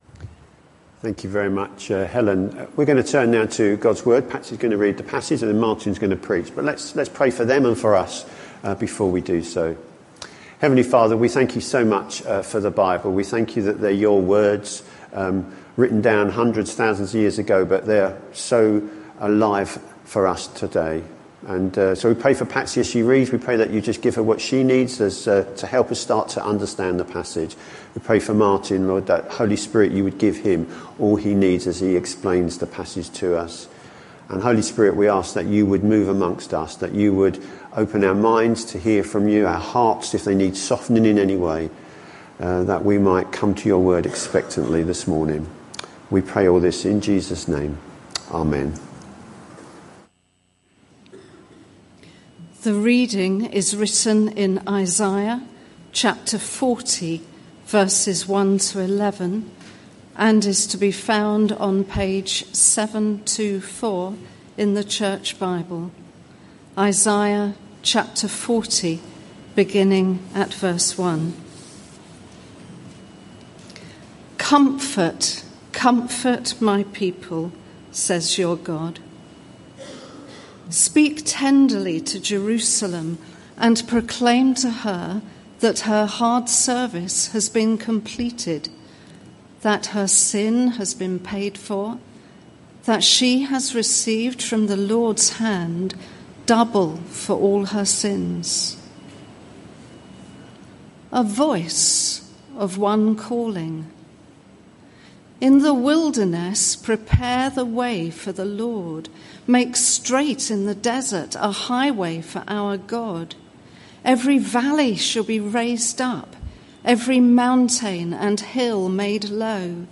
This sermon is part of a series: 8 December 2024